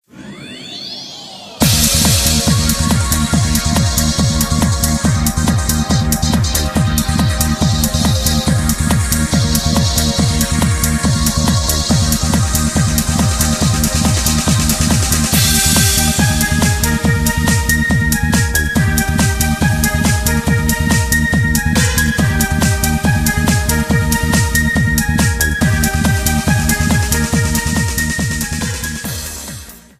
Trimmed and fadeout
Fair use music sample